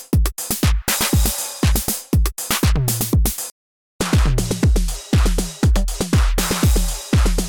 9x9 Set preset vs. tweaked: